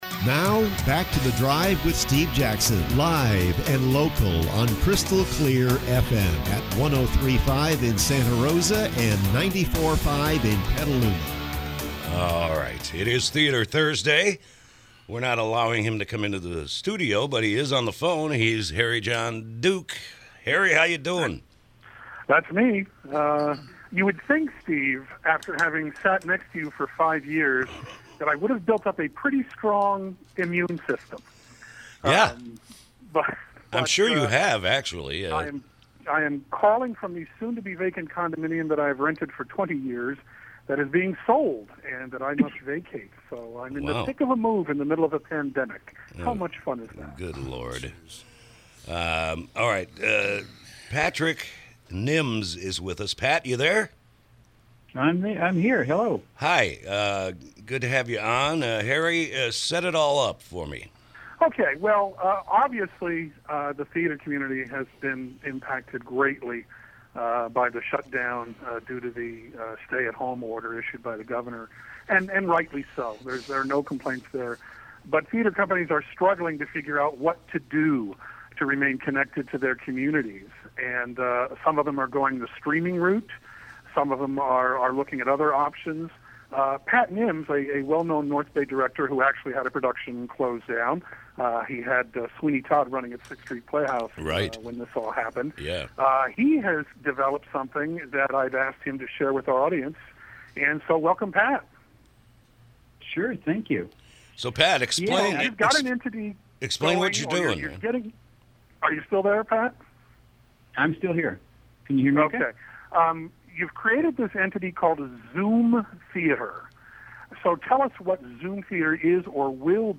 KSRO Interview
I apologize in advance for the audio quality. but if you turn your speakers up, you should be able to hear it.